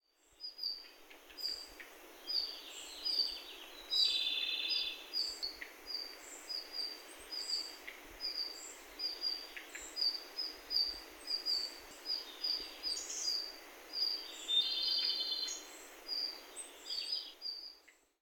１　ルリビタキ【瑠璃鶲】　全長約14cm
【録音3】 　2025年8月5日　日光白根山
地鳴き・・「ヒッ、ヒッ」「カッ、カッ」に「ギッ、ギッ」という声が聞こえる